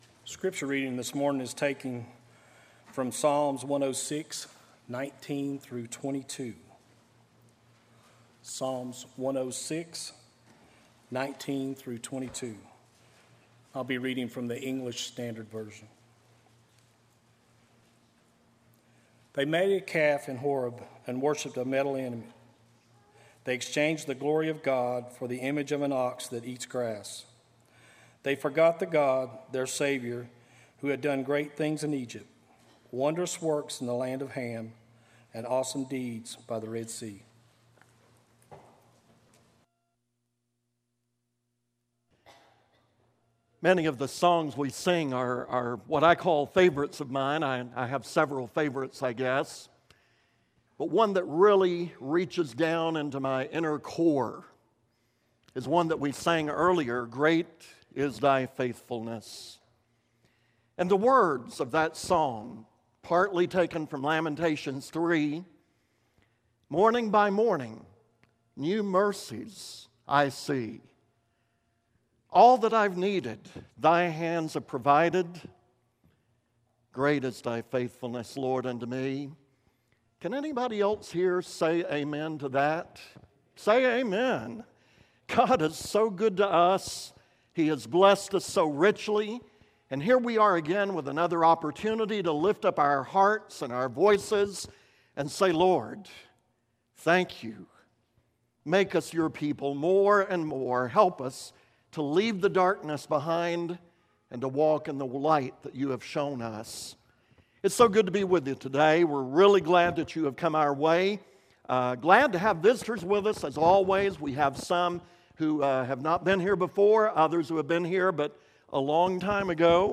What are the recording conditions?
Series: Sunday AM Service